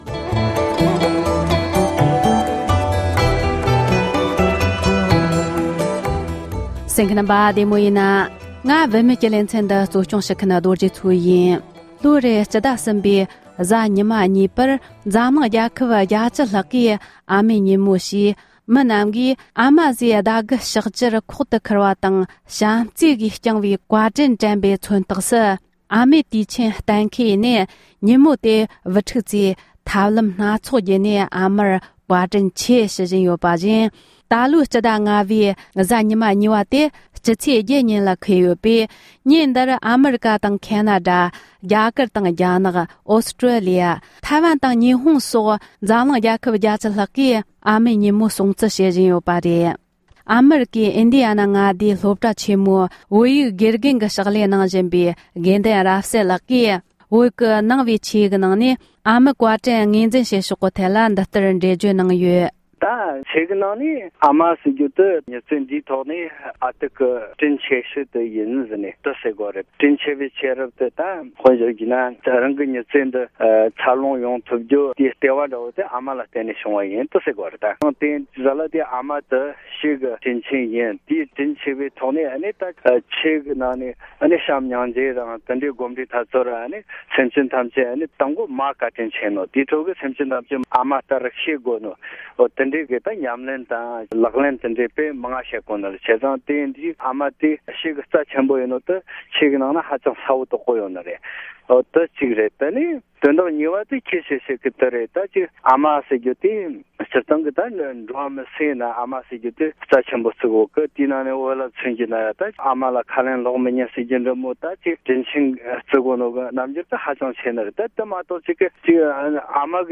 གླེང་མོལ་བྱས་པར་ཉན་རོགས༎